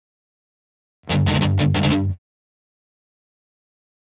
Guitar Fast Heavy Metal Rhythm